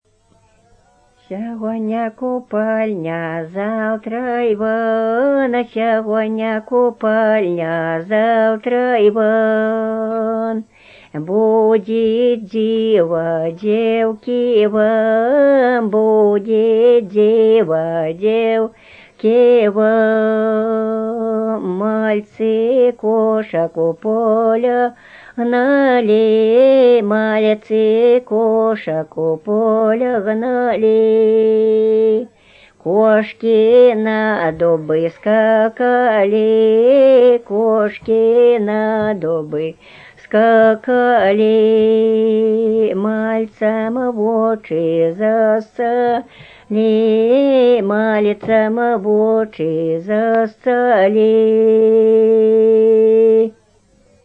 Купальные обрядовые песни Невельского района